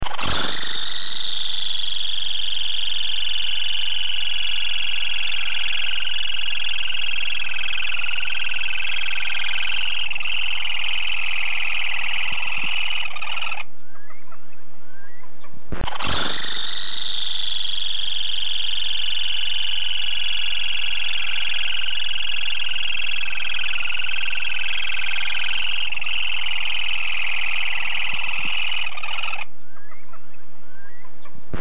Laterallus melanophaius melanophaius - Burrito patas verdes